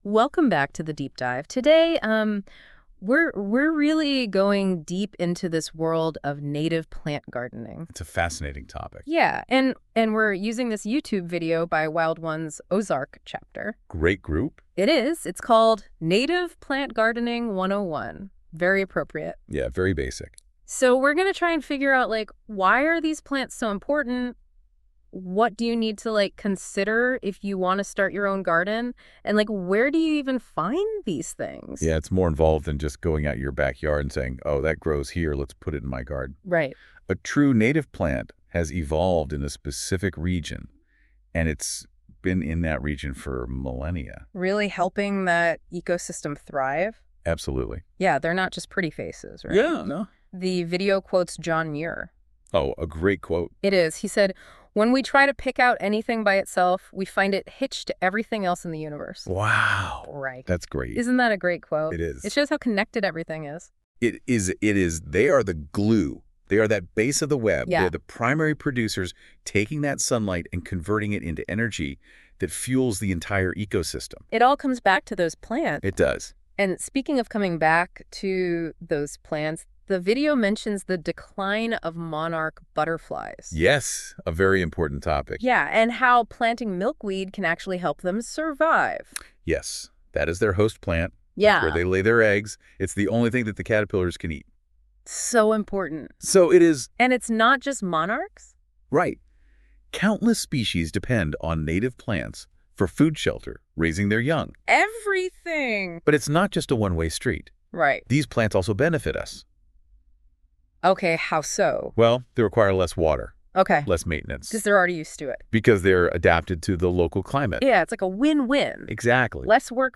"Native Plant Gardening 101" presentation by Wild Ones Ozark Chapter.